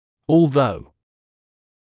[ɔːl’ðəu][оːл’зоу]хотя, если бы даже